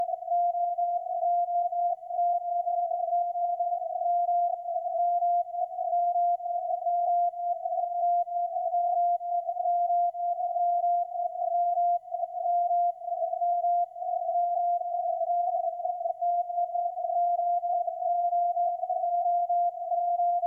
beacon il monte...
20:45z       Le beacon devient QRO...
Sa fréquence centrale semble être sur 1809
et la dernière raie du peigne sue 1820.5...
beacon-9m4.mp3